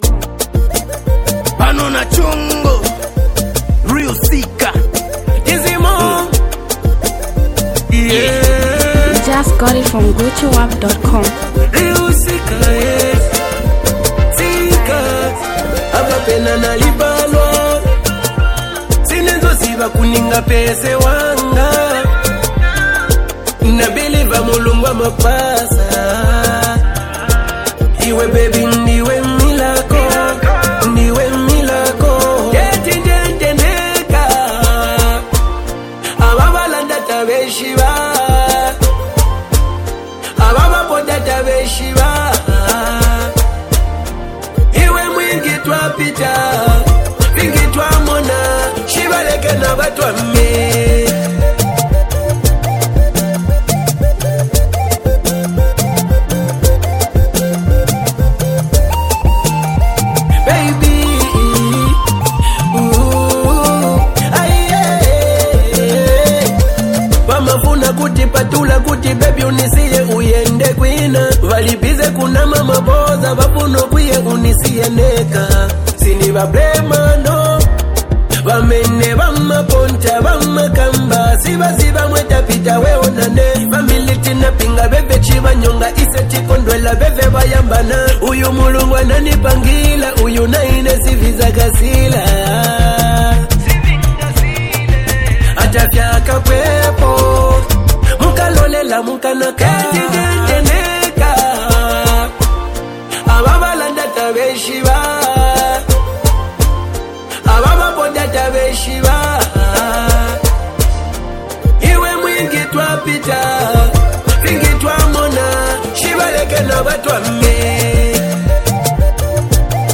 a hardcore rapper
this song blends soulful vocals with powerful rap verses.